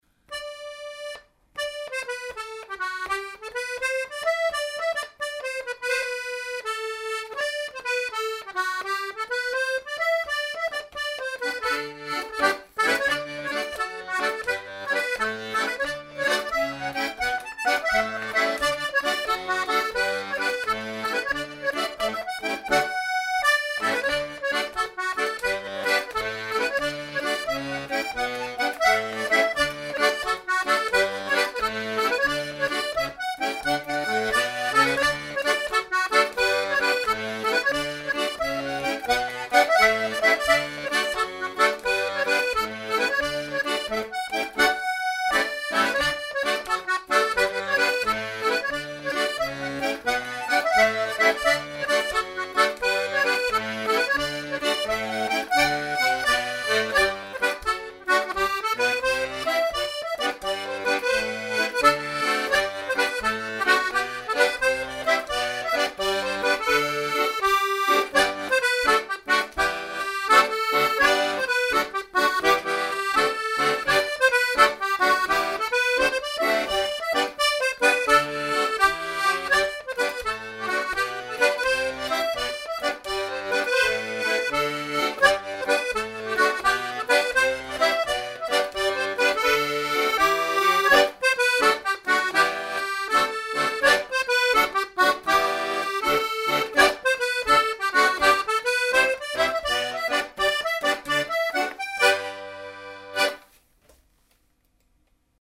The mp3 files are low quality to economise on disc space.